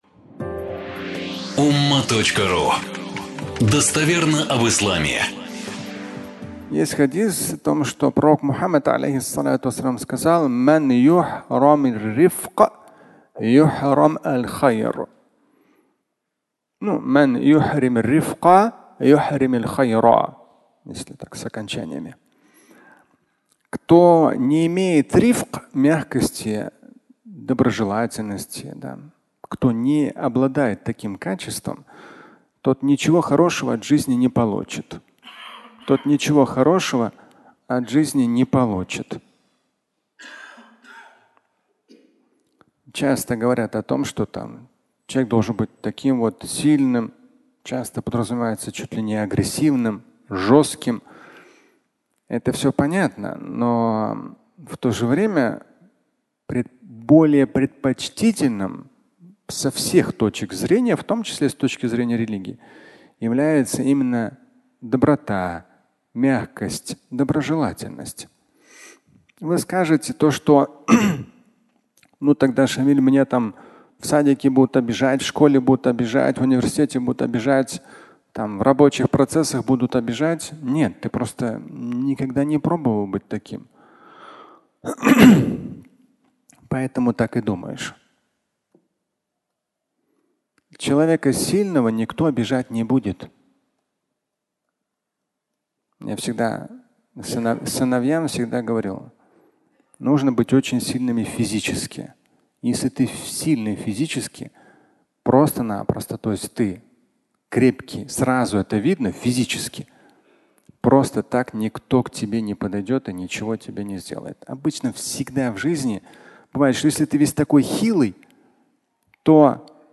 Доброта (аудиолекция)
Пятничная проповедь